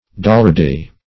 Search Result for " dollardee" : The Collaborative International Dictionary of English v.0.48: Dollardee \Dol`lar*dee"\, n. (Zool.)